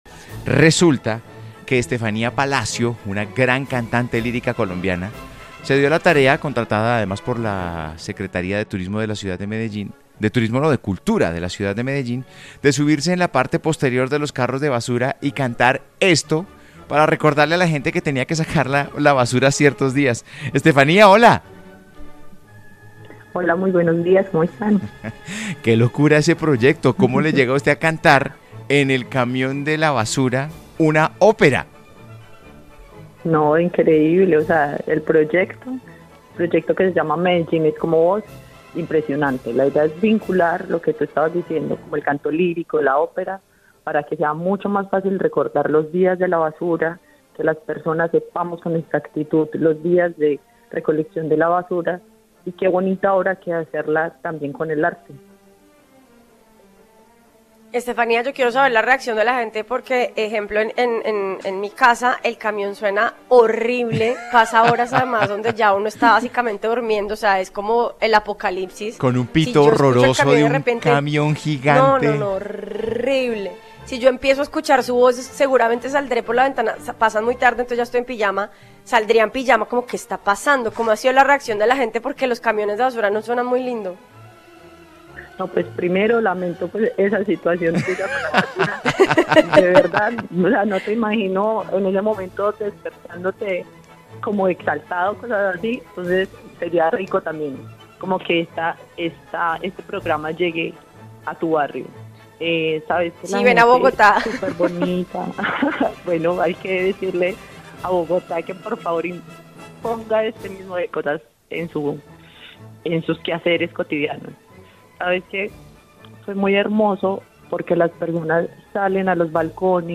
Cantante Lirica